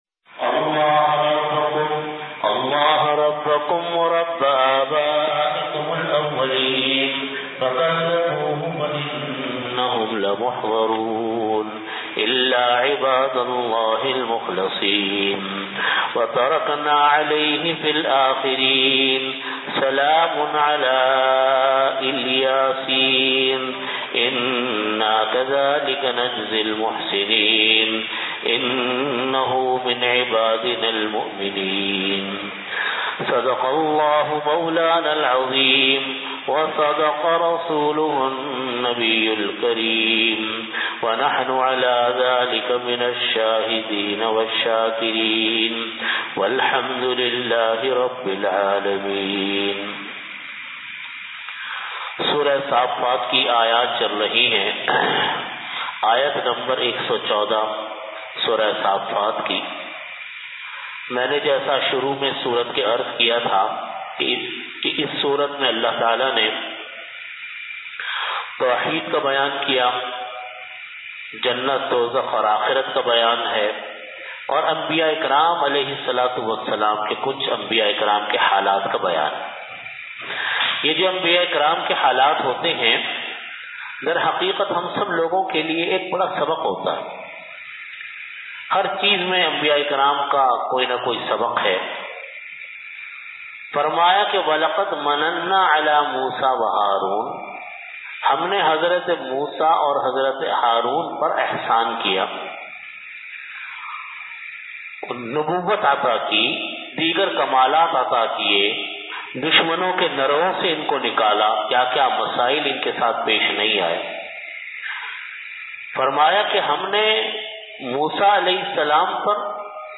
Delivered at Jamia Masjid Bait-ul-Mukkaram, Karachi.
Tafseer · Jamia Masjid Bait-ul-Mukkaram, Karachi